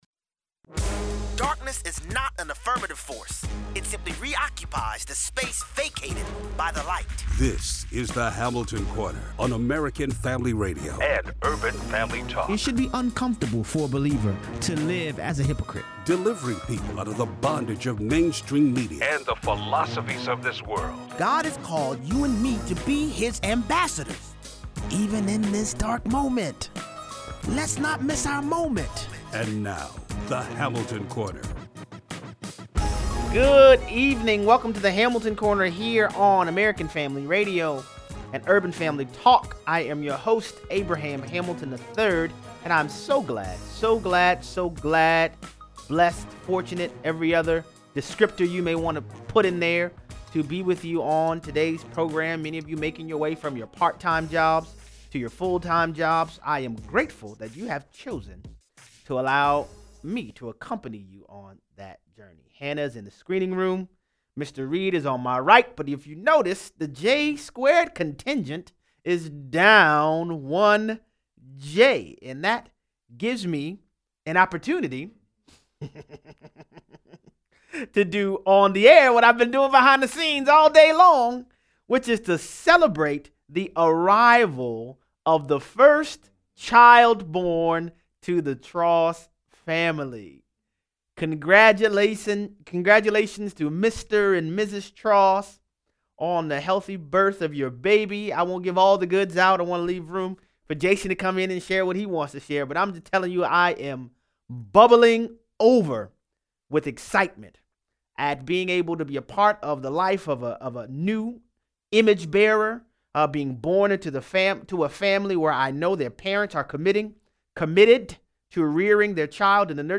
Chelsea Clinton argues that in-utero baby murder has great economic benefit... it boosts the American economy. 0:43 - 0:60: How far should we take Chelsea Clinton’s logic? Callers weigh in.